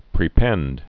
(prē-pĕnd)